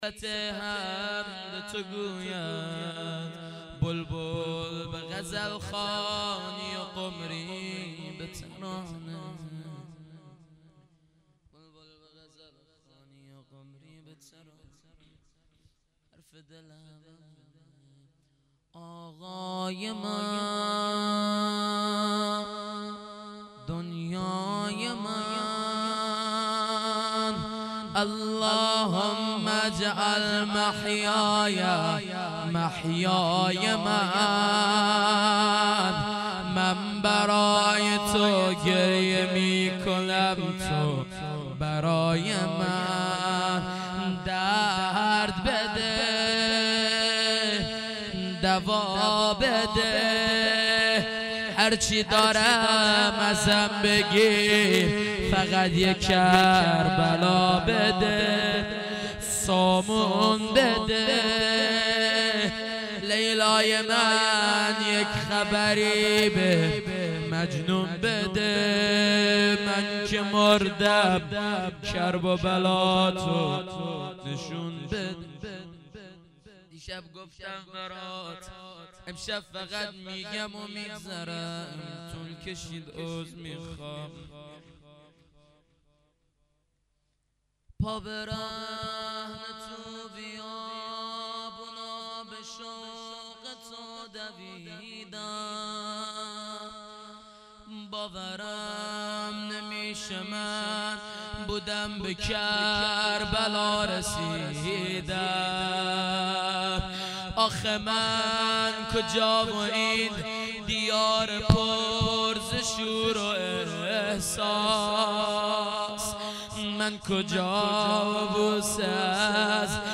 heiat-levaolabbasshab-shahadat-hazrat-roghaieh-rozeh-paiani.mp3